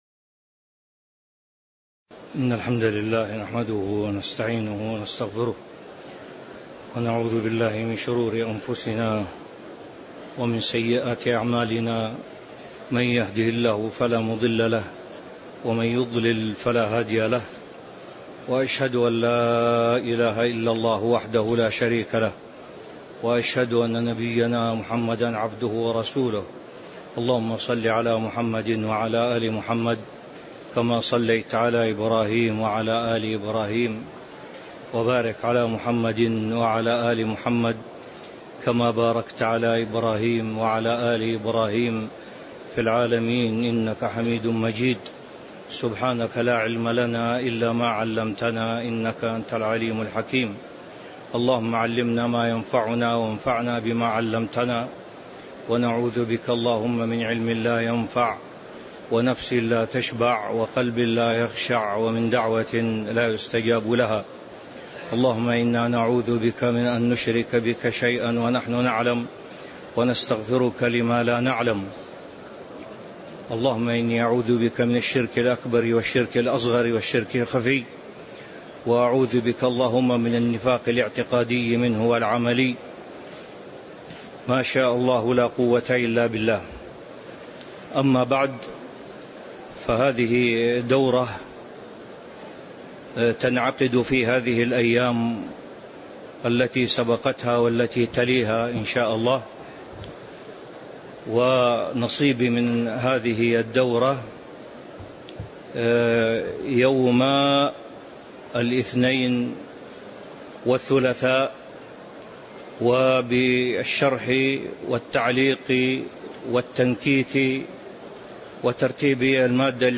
تاريخ النشر ٢٢ جمادى الآخرة ١٤٤٦ هـ المكان: المسجد النبوي الشيخ